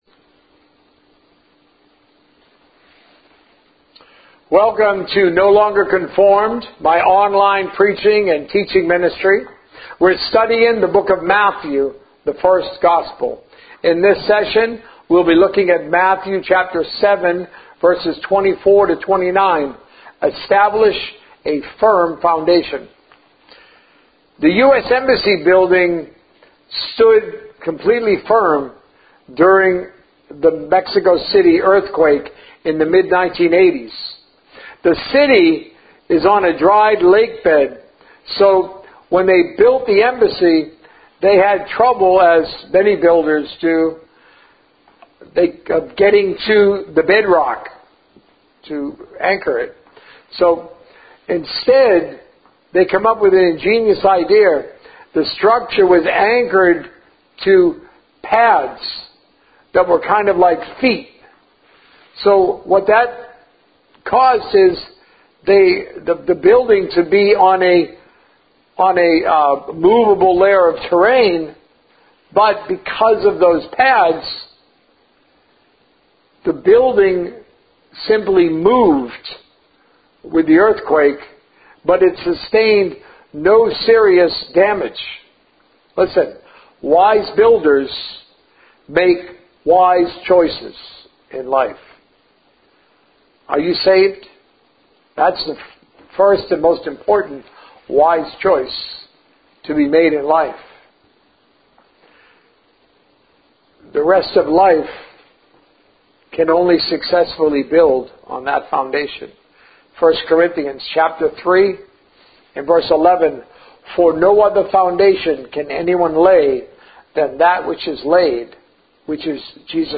A message from the series "The First Gospel." Establish a Firm Foundation